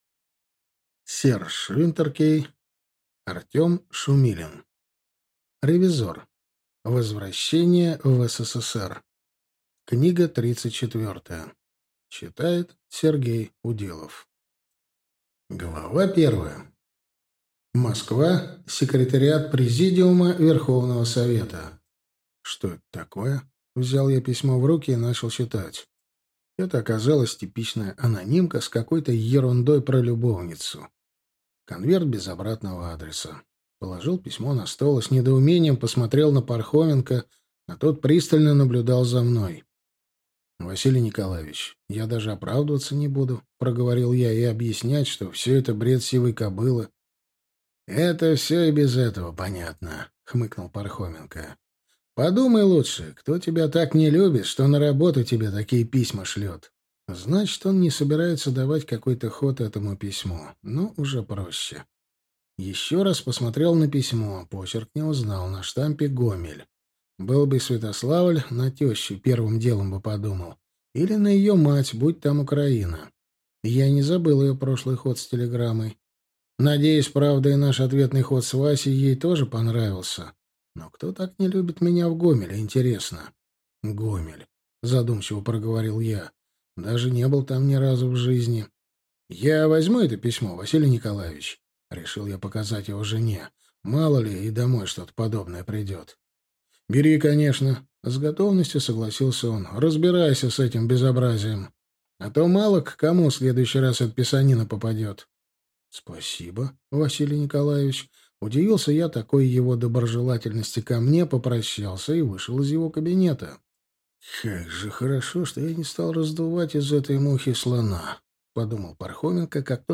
Слушать аудиокнигу Ревизор: возвращение в СССР 34 полностью